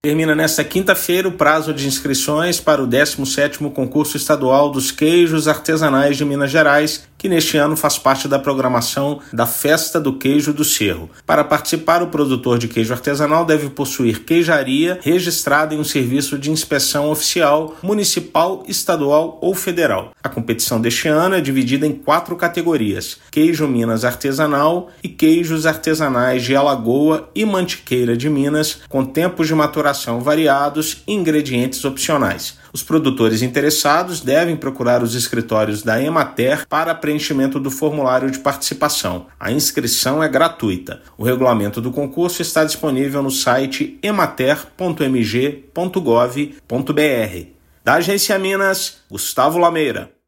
Promovido pela Emater-MG, concurso deste ano conta com quatro categorias. Ouça matéria de rádio.